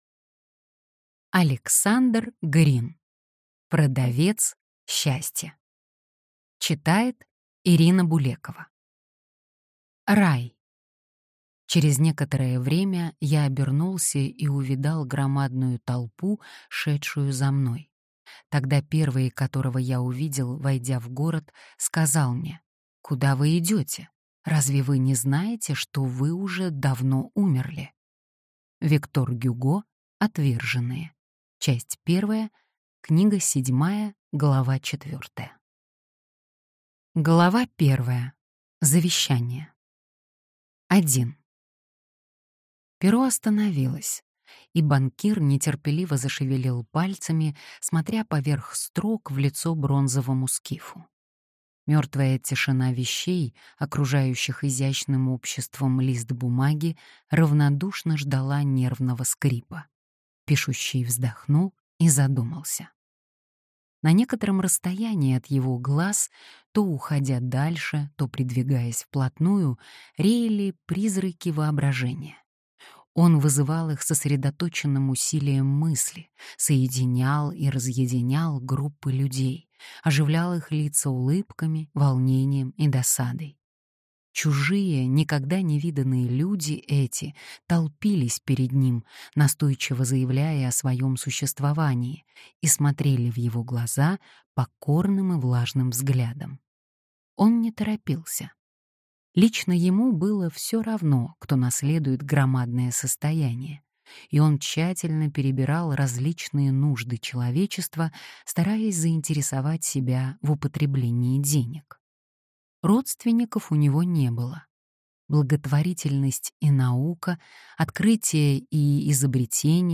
Аудиокнига Продавец счастья | Библиотека аудиокниг